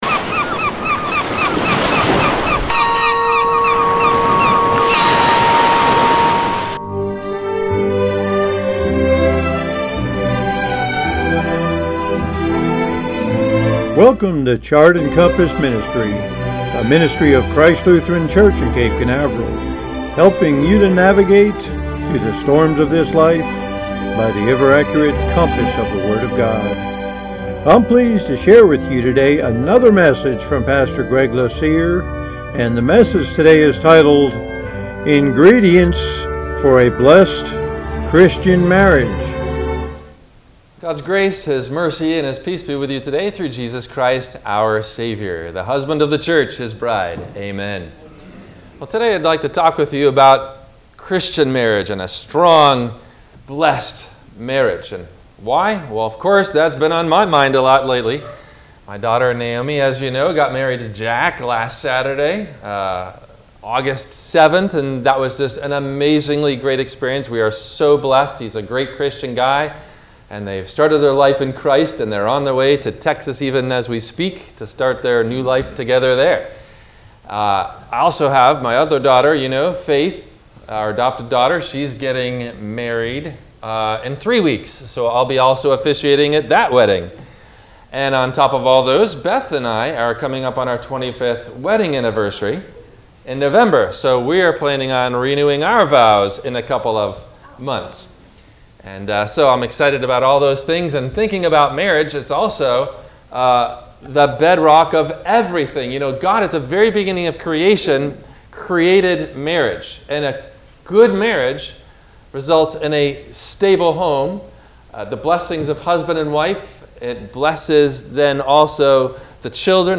WMIE Radio – Christ Lutheran Church, Cape Canaveral on Mondays from 12:30 – 1:00